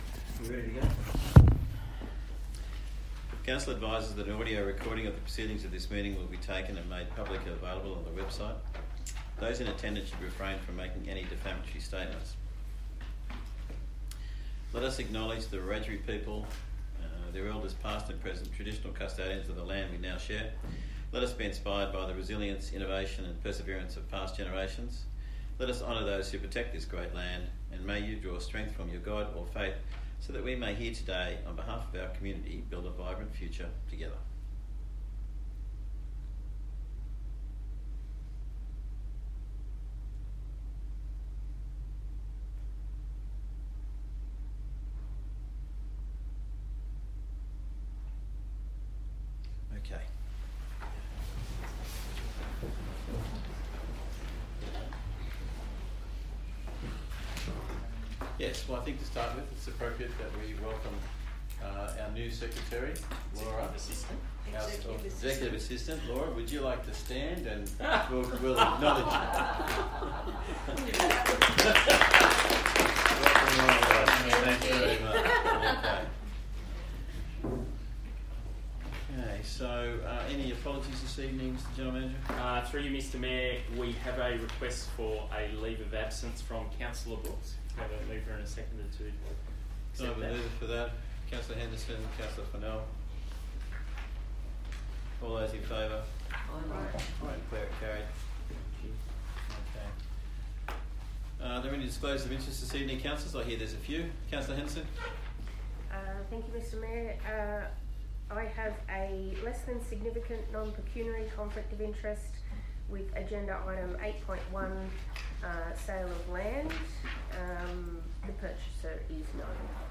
18 November 2025 Ordinary Council Meeting
Bland Shire Council Chambers, 6 Shire Street, West Wyalong, 2671 View Map